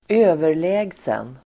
Uttal: [²'ö:ver_lä:gsen]